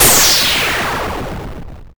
corexplode.mp3